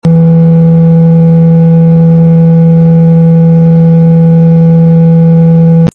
Cooling Sound Sound Effects Free Download